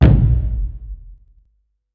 Big Drum Hit 35.wav